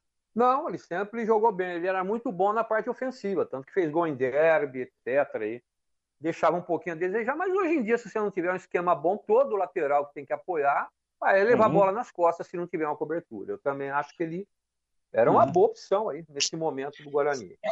Comentaristas da Rádio Brasil analisam as movimentações da janela de transferências